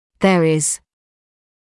[ðə’rɪz][зэ’риз]оборот, означающий наличие или отсутствие кого-л. или чего-л.